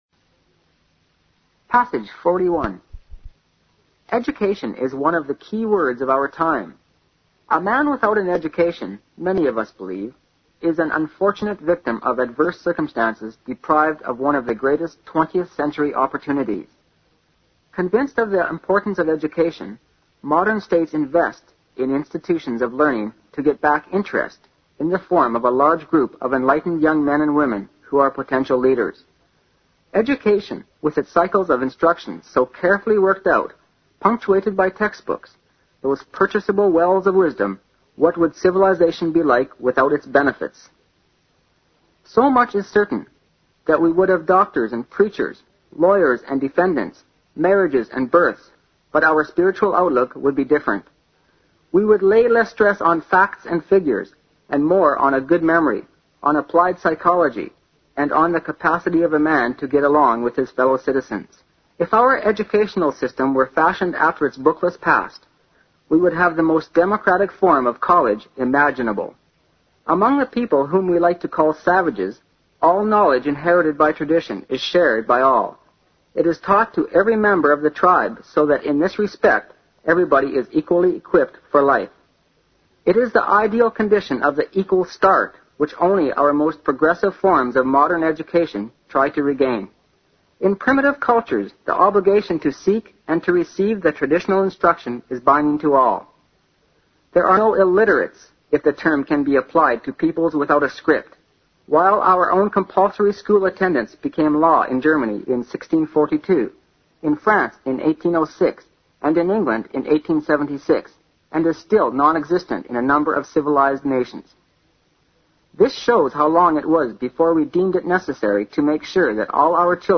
新概念英语85年上外美音版第四册 第41课 听力文件下载—在线英语听力室